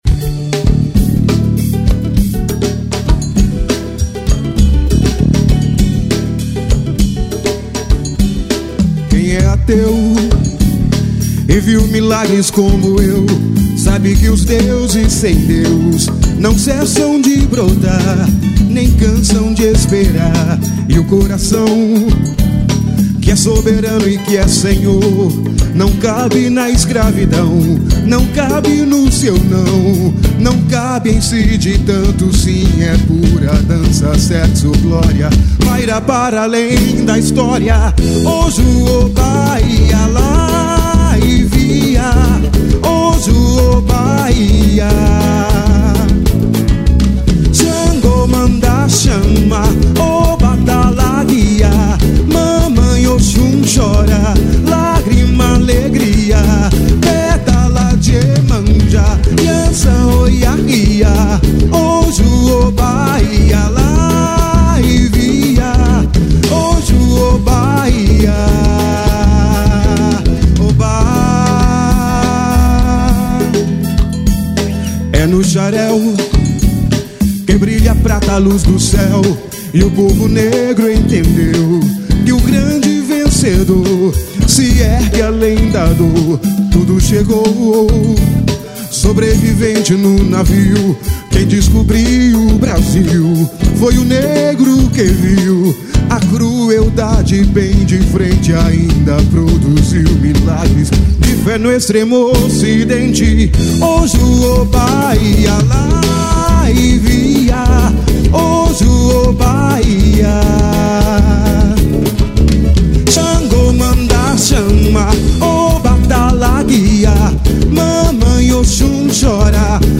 EstiloSamba